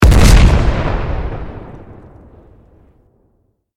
medium-explosion-5.ogg